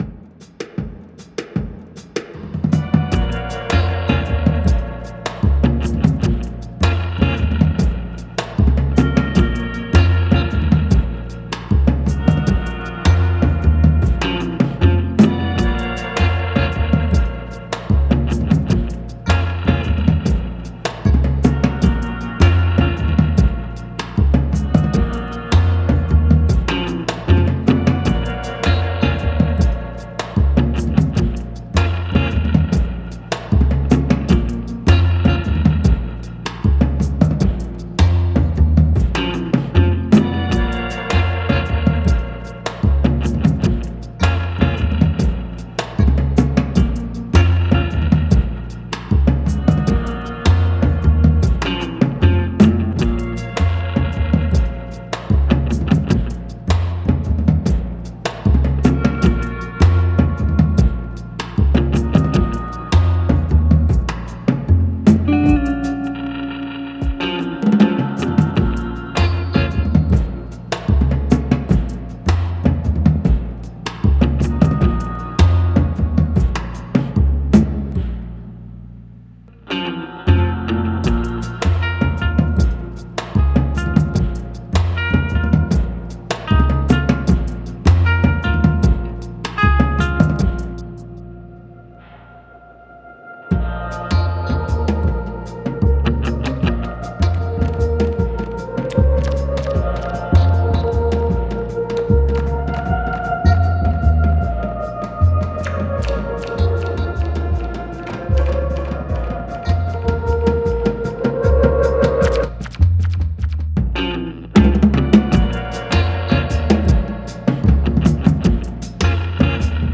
Dark scores move with menace.